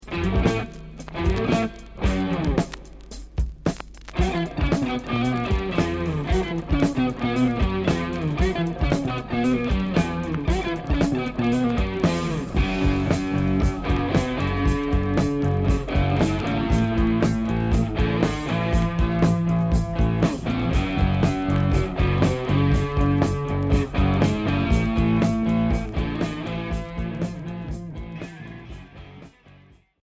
Heavy rock Premier 45t retour à l'accueil